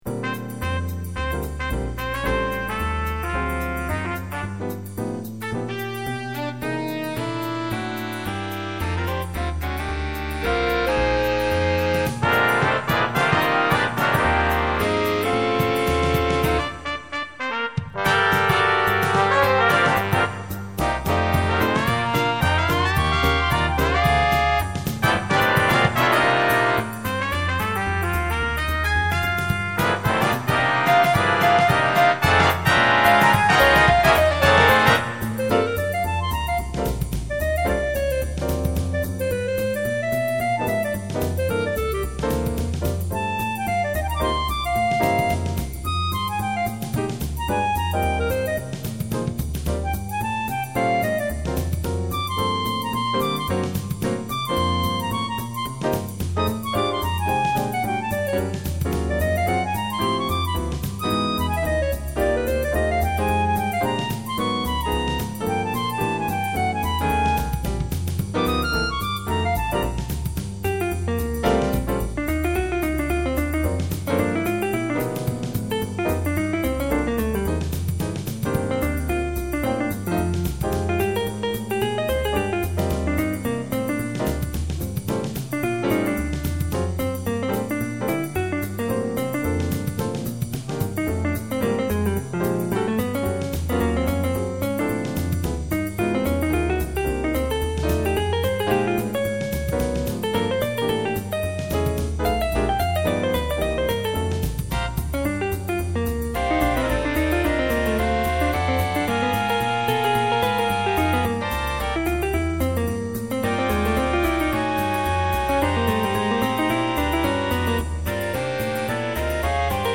Big Band Arrangements